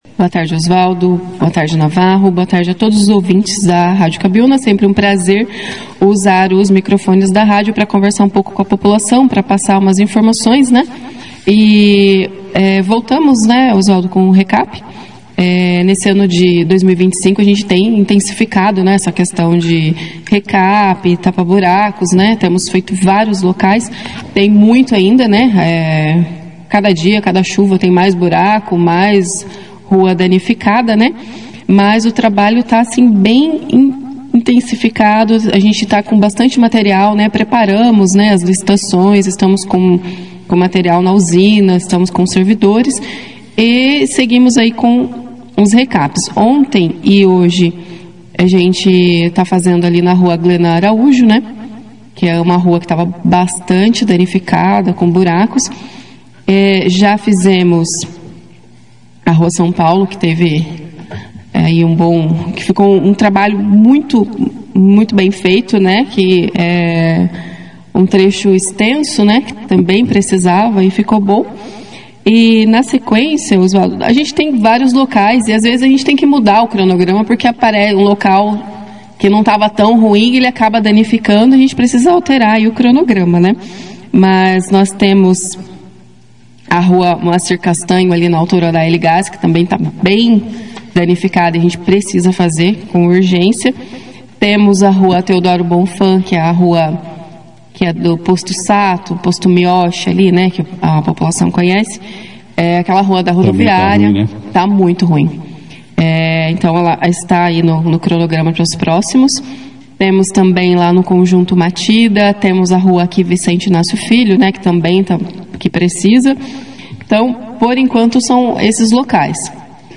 A secretária de Administração da Prefeitura de Bandeirantes, Dra. Cláudia Jans, (foto),participou da 2ª edição do Jornal Operação Cidade, nesta quarta-feira, 31 de julho, e falou sobre o cronograma da operação tapa-buraco, que segue atendendo diversos bairros da cidade com serviços de recape, pavimentação e manutenção das vias.